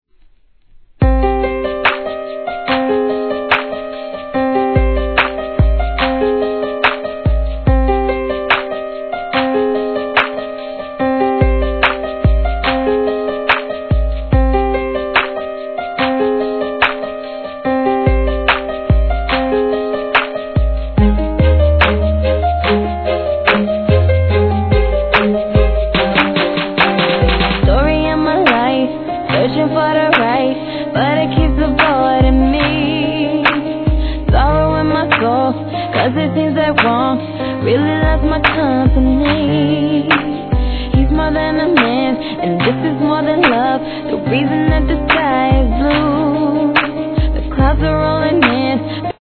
HIP HOP/R&B
テンションガチ上げ系でお馴染みス!